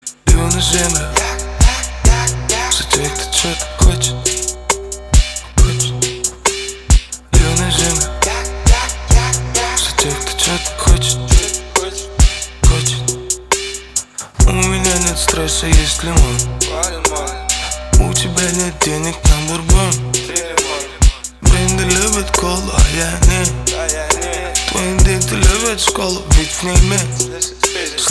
• Качество: 320, Stereo
мужской голос
русский рэп
релакс